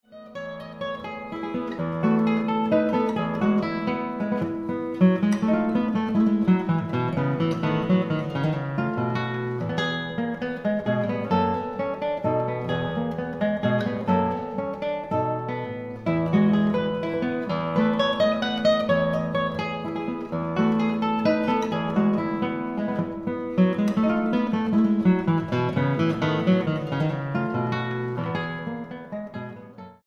capricho